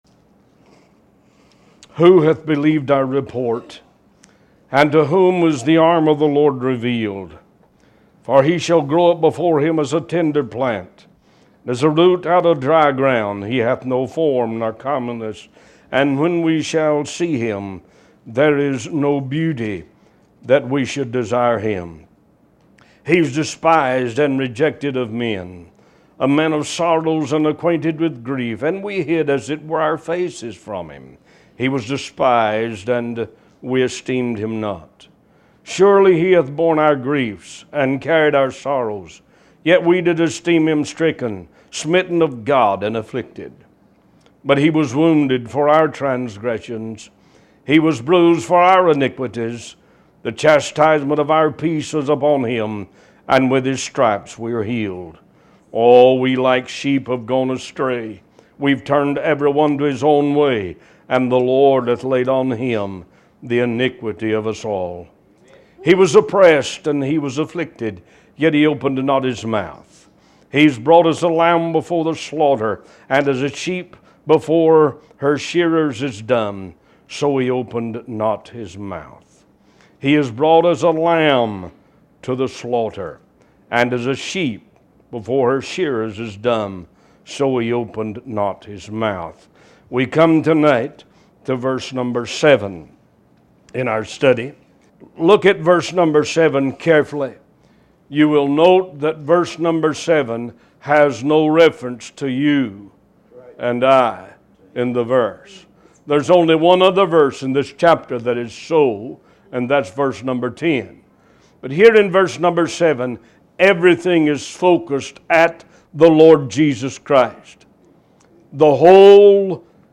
Talk Show Episode, Audio Podcast, One Voice and Isaiah 53 Series 124 on , show guests , about Isaiah 53, categorized as Health & Lifestyle,History,Love & Relationships,Philosophy,Psychology,Christianity,Inspirational,Motivational,Society and Culture